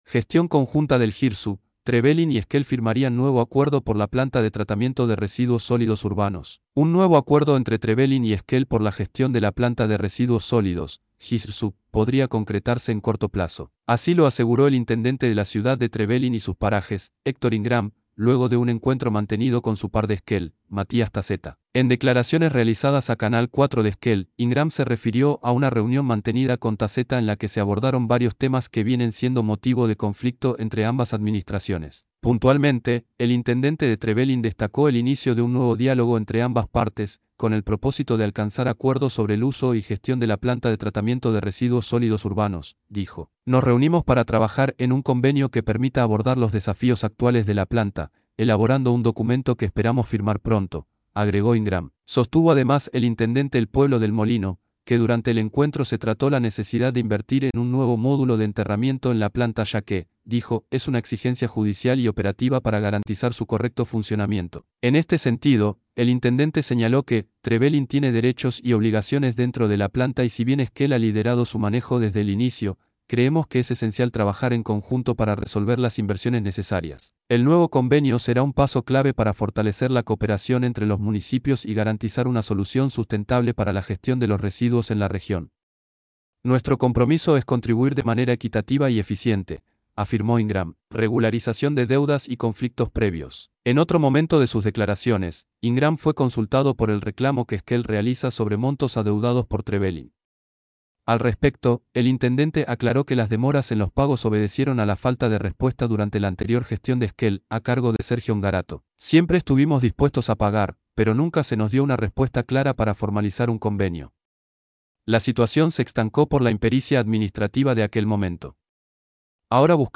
Así lo aseguró el intendente de la ciudad de Trevelin y sus Parajes, Héctor Ingram, luego de un encuentro mantenido con su par de Esquel, Matías Taccetta. En declaraciones realizadas a Canal 4 de Esquel, Ingram se refirió a una reunión mantenida con Taccetta en la que se abordaron varios temas que vienen siendo motivo de conflicto entre ambas administraciones.